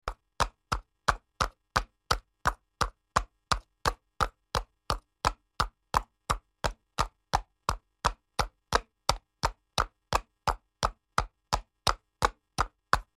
جلوه های صوتی
دانلود صدای اسب 3 از ساعد نیوز با لینک مستقیم و کیفیت بالا